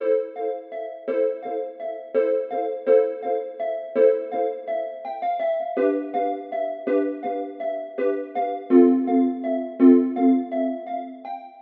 枪声 " 毛瑟枪 3.08 2
描述：现场录制的毛瑟枪3.08枪的远距离录音。用H2变焦器记录的。
Tag: 泰罗斯 disparos 毛瑟 阿马斯 步枪射击 步枪 火武器